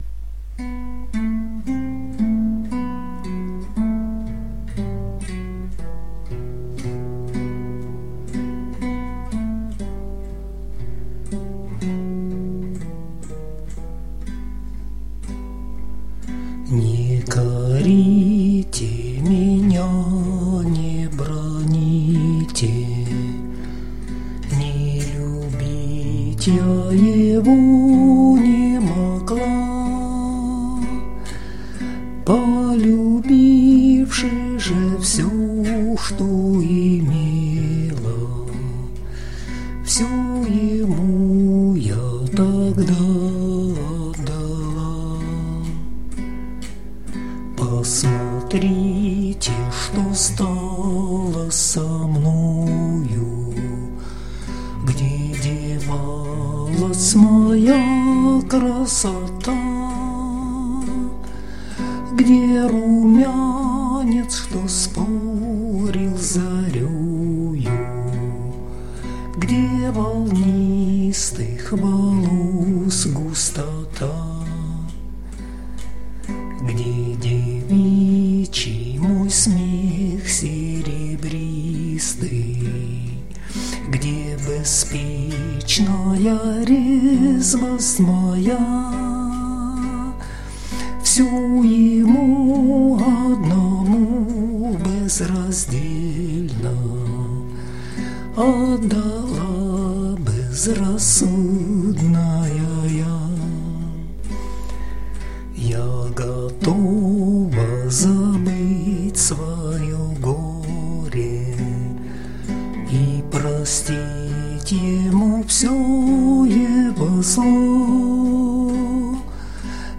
../icons/neslyshn.jpg   Русская народная песня